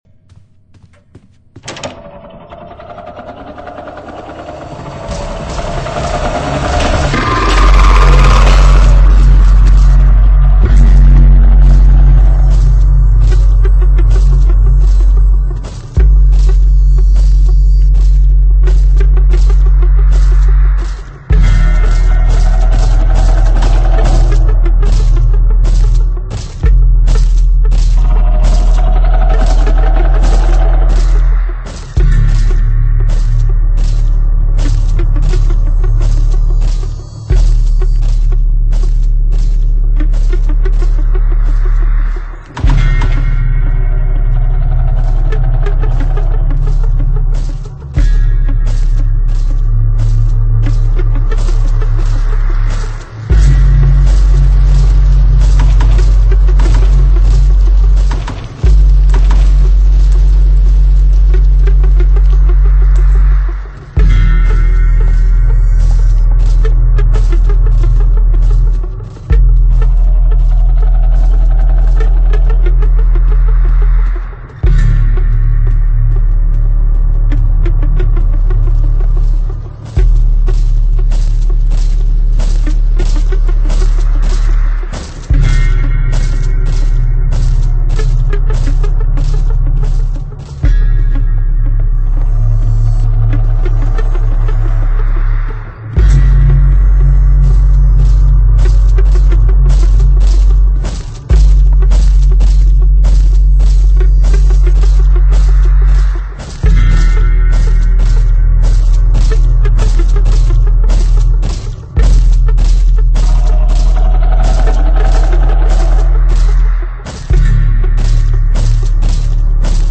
Roblox doors part 2 speedrun level 50-75 full gameplay with pc